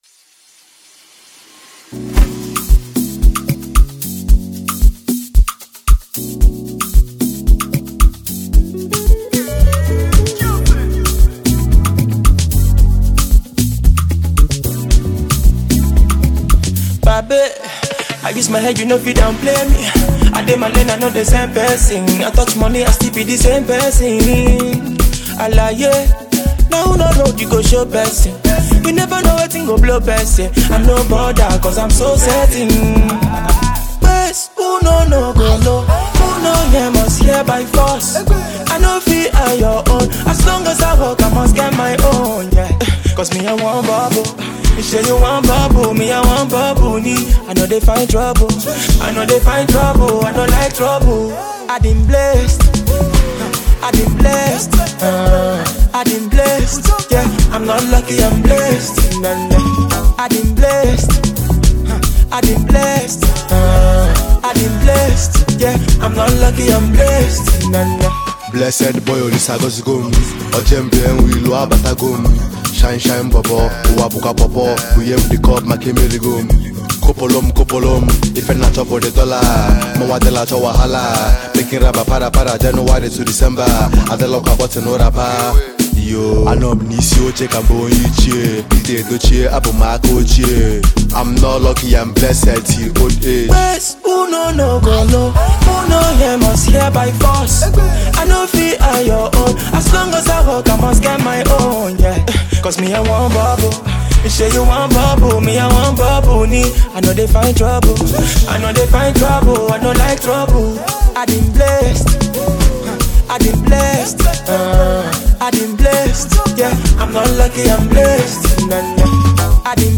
melodic and infectious new track